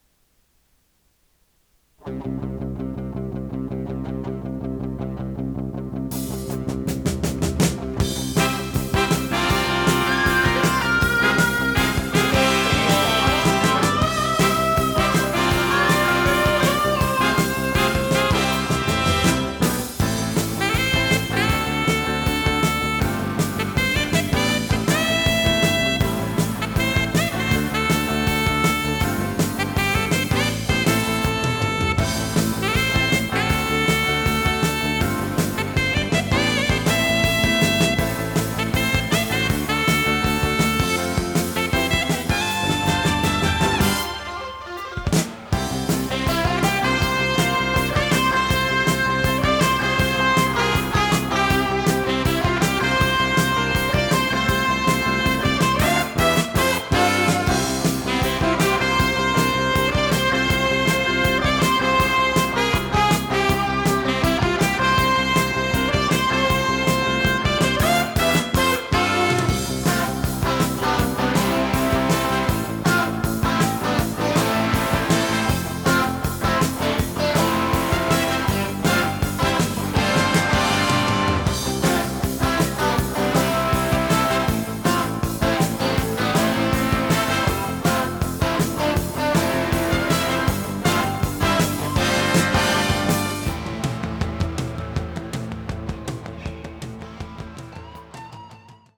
テープ：RTM
ノイズリダクションOFF
【フュージョン・ロック】96kHz-24bit 容量54.3MB